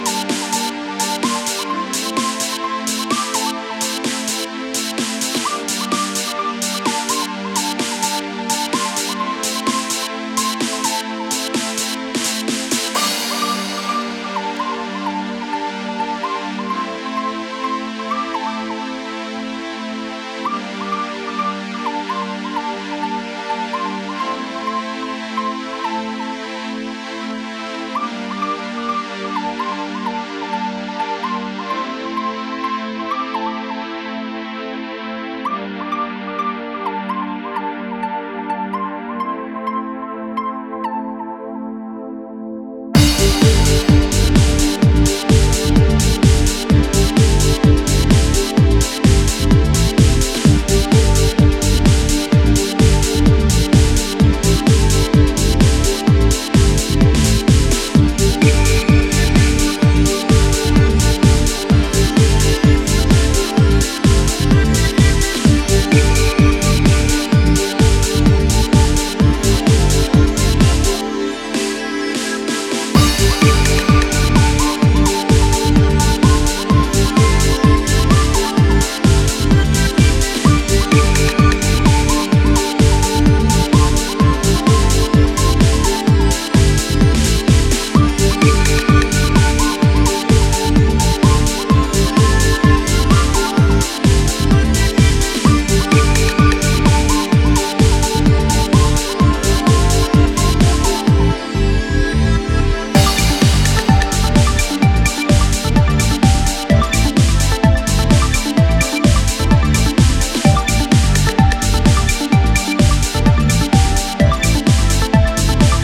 immediate dancefloor smasher